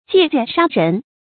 借剑杀人 jiè jiàn shā rén
借剑杀人发音